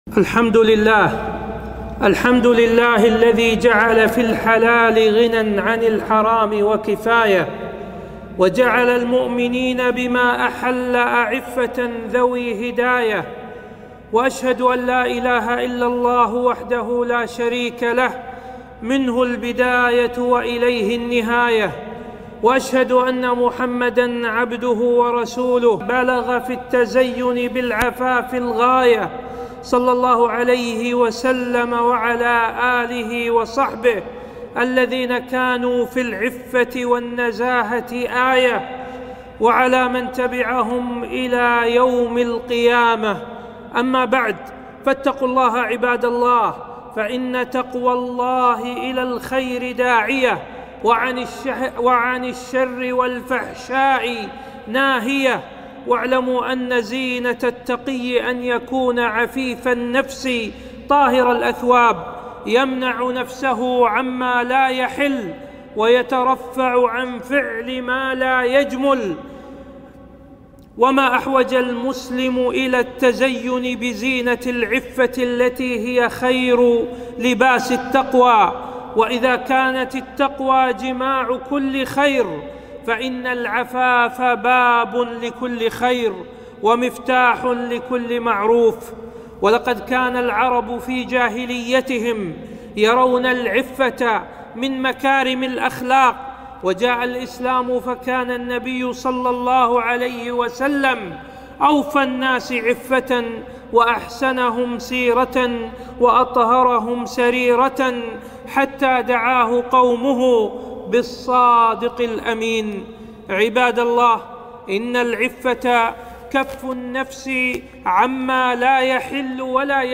خطبة - فضل العفة وأسباب الاستعفاف